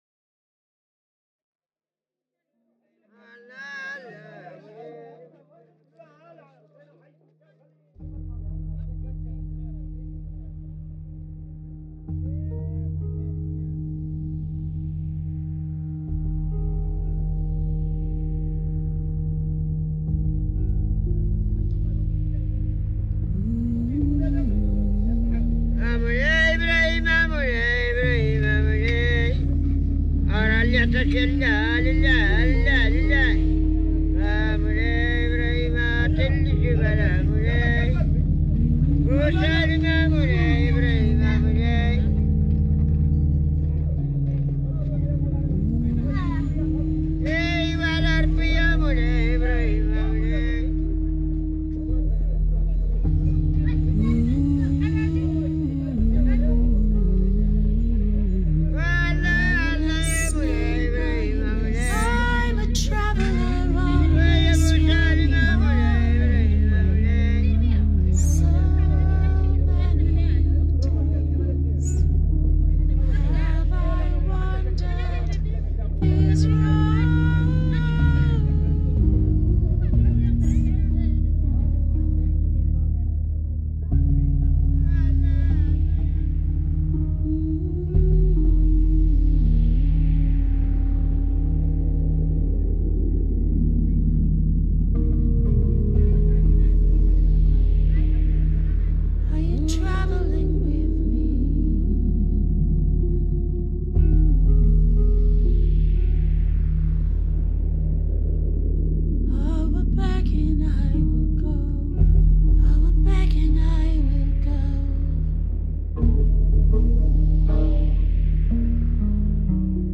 This piece is built around a field recording from the sound collections of the Pitt Rivers Museum of Berber beggars singing for charity.